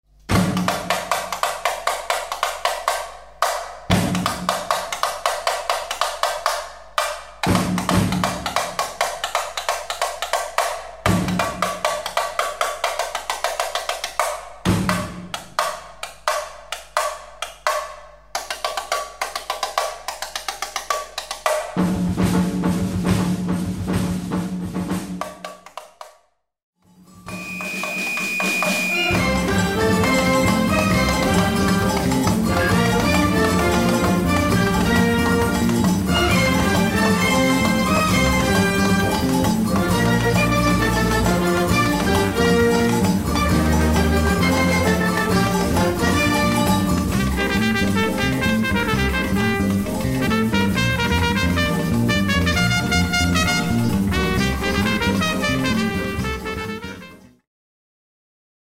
Gattung: Flexible instrumentation
4:00 Minuten Besetzung: Blasorchester Zu hören auf